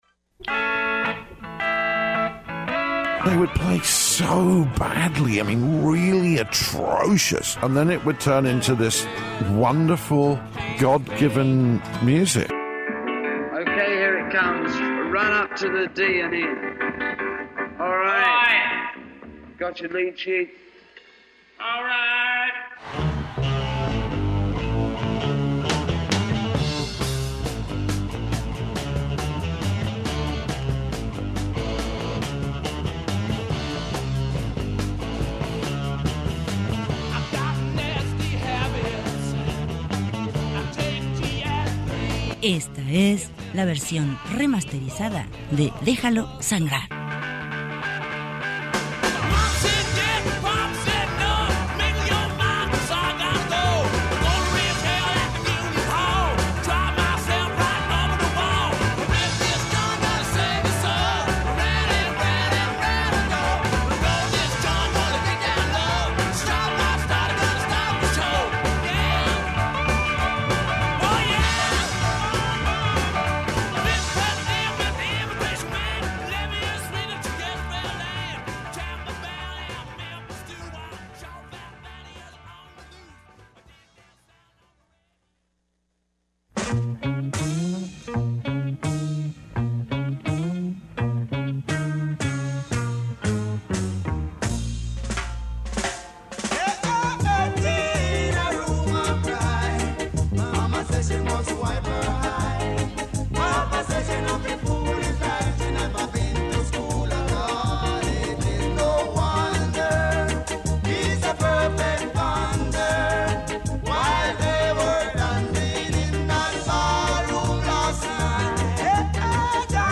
música negra